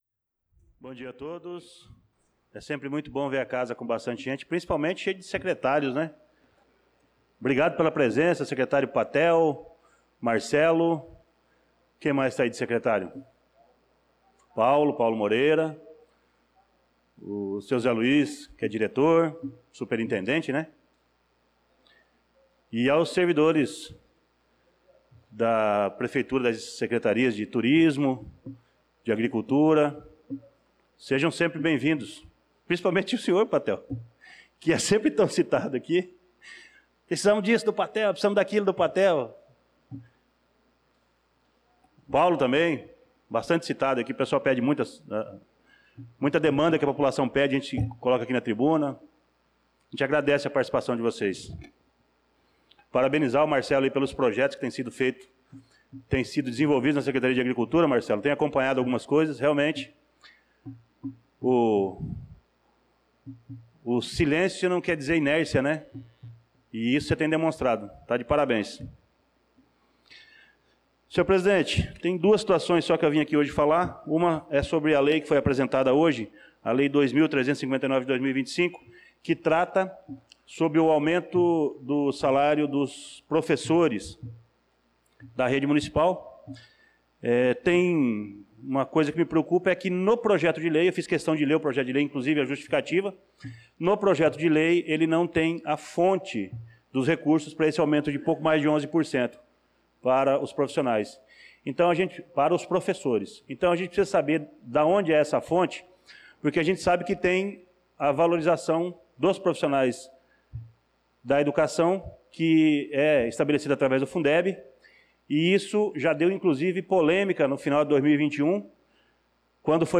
Pronunciamento do vereador Luciano Silva na Sessão Ordinária do dia 07/07/2025.